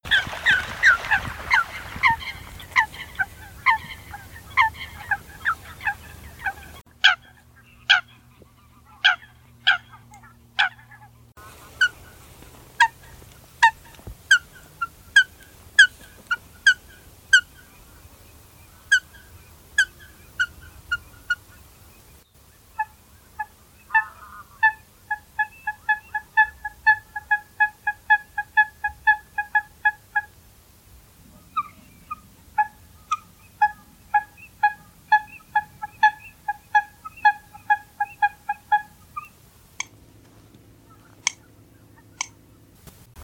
meerkoet
🔭 Wetenschappelijk: Fulica atra
♪ contactroep
meerkoet_roep_def.mp3